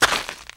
HauntedBloodlines/STEPS Gravel, Walk 09.wav at main
STEPS Gravel, Walk 09.wav